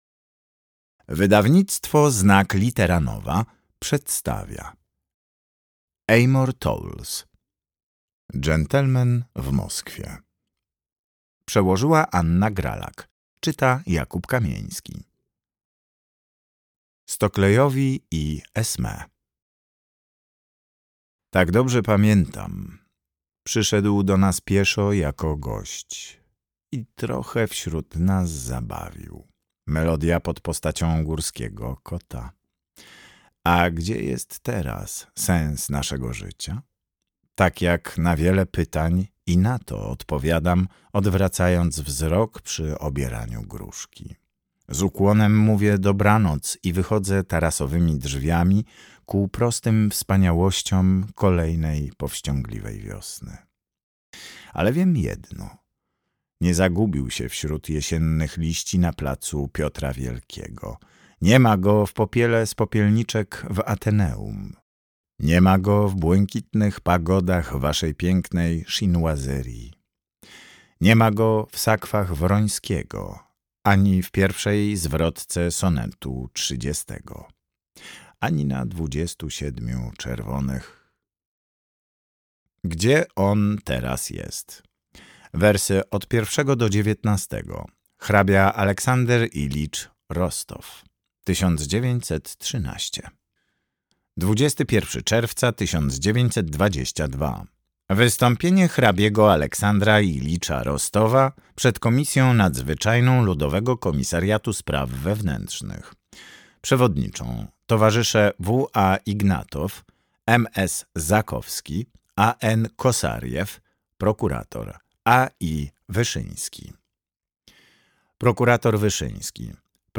Dżentelmen w Moskwie - Amor Towles - audiobook